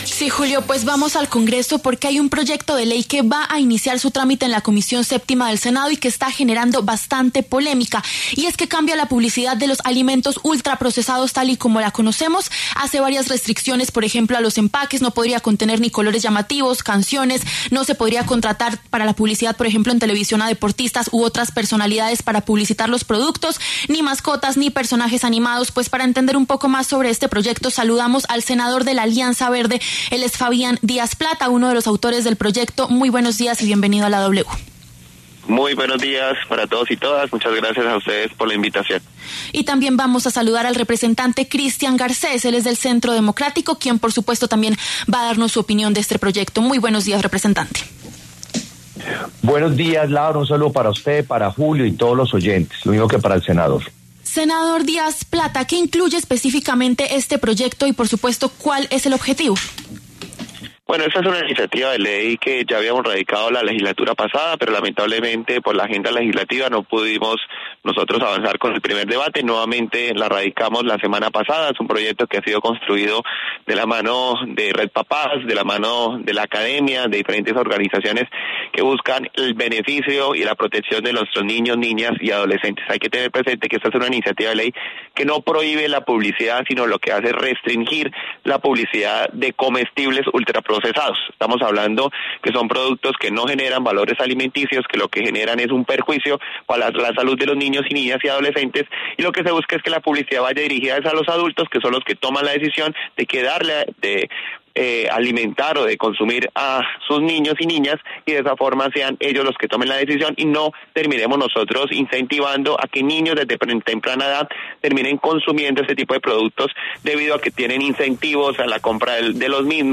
El senador Fabián Díaz Plata, de la Alianza Verde y autor del proyecto, paso por los micrófonos de La W. También el representante Christian Garcés, del Centro Democrático.